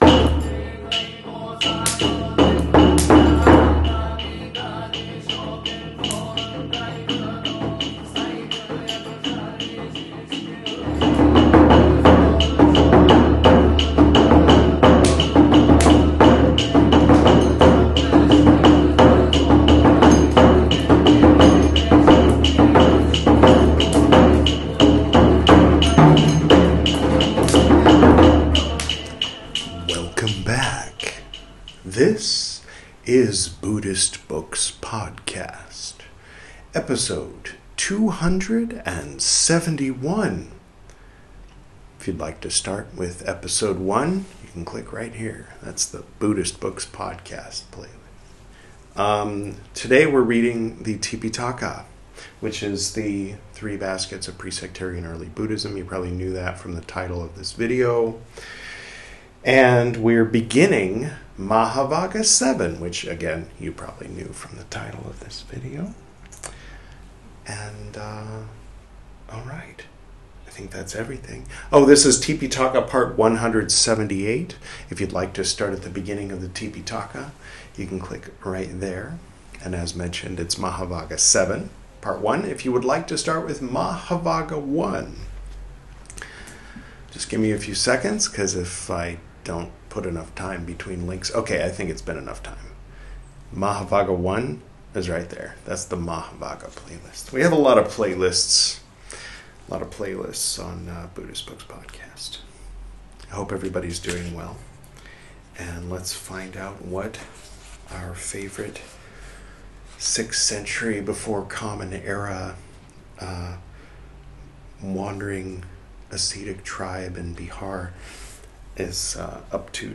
This is Part 178 of my recital of the 'Tipiṭaka,' the 'Three Baskets' of pre-sectarian Buddhism, as translated into English from the original Pali Language. In this episode, we'll begin reading 'Mahāvagga VII,' from the 'Vinaya Piṭaka,' the first of the three 'Piṭaka,' or 'Baskets.'